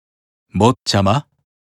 Chat Voice Files
Speaker Barbatos